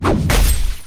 🌲 / foundry13data Data modules soundfxlibrary Combat Single Melee Hit
melee-hit-4.mp3